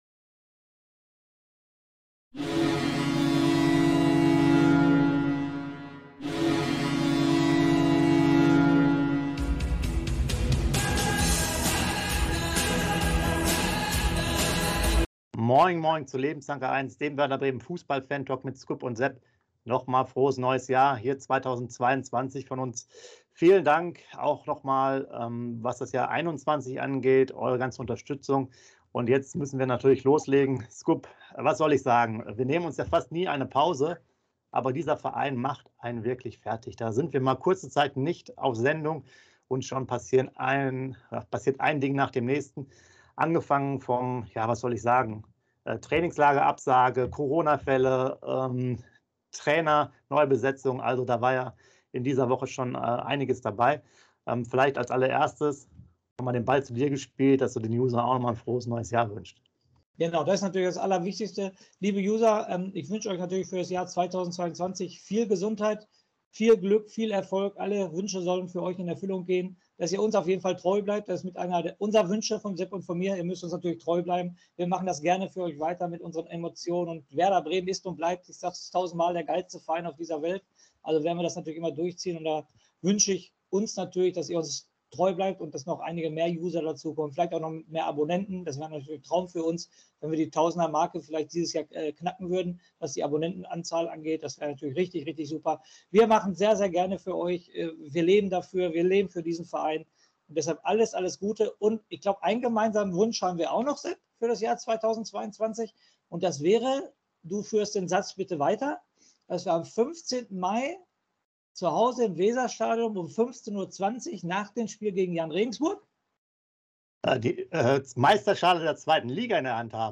Werder Bremen – News & Aktuelles vom 08.01.2022 ~ Werder Bremen - Fußball Fantalk Lebenslang-A1 Podcast